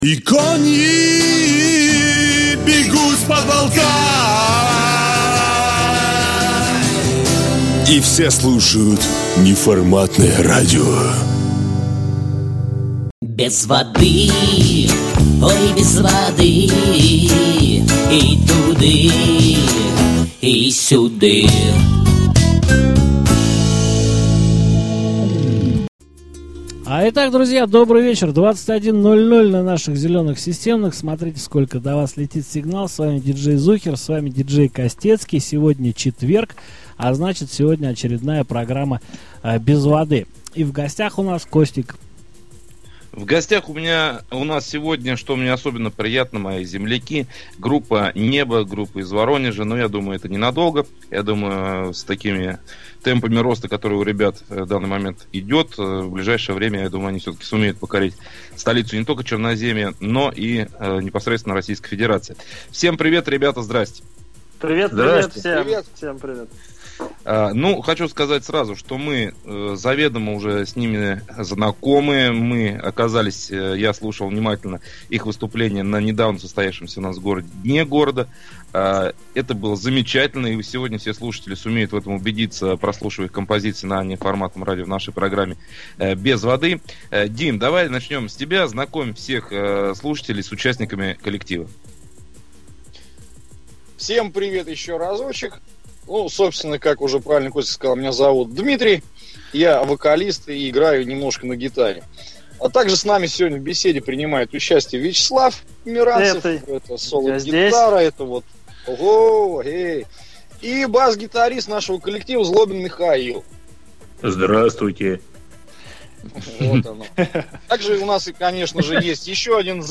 был замечательный квартет из Воронежа